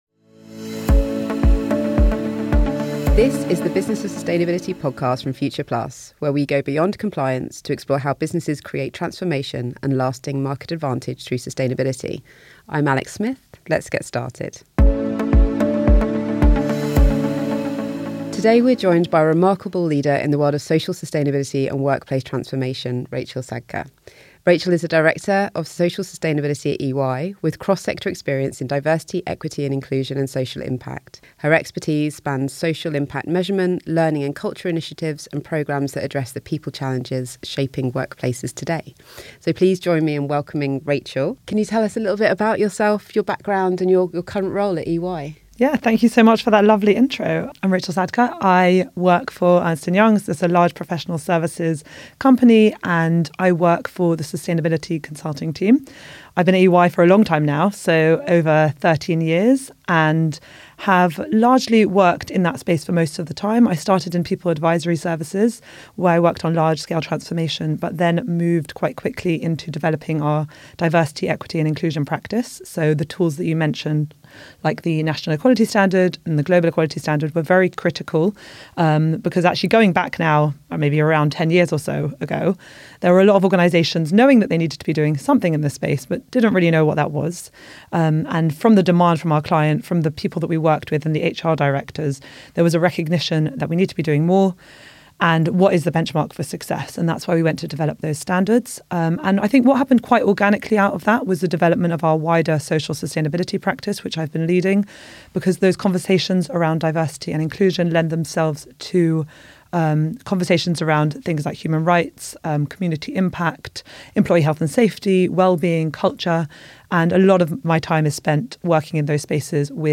The conversation covers navigating DEI backlash, measuring social impact, embedding sustainability across all business functions, and why socioeconomic diversity matters.